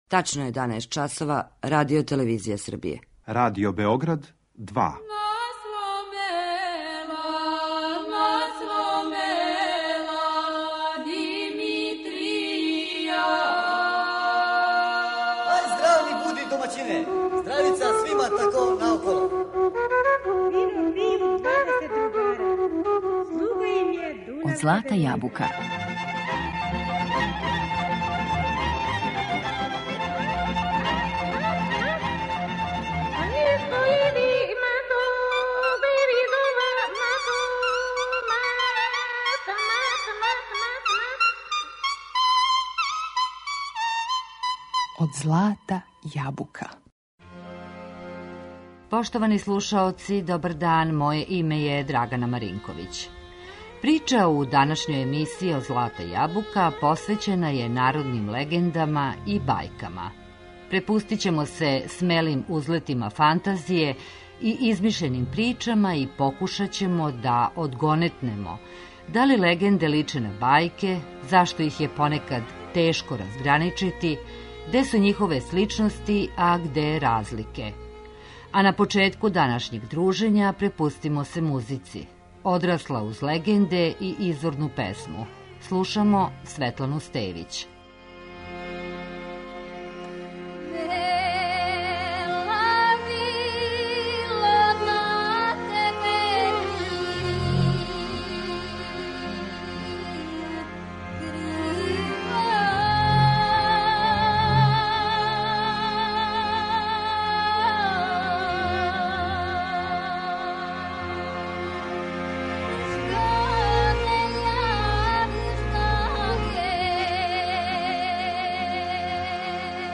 И традиционалну народну музику, која прати нашу причу, вековима је стварала машта народа.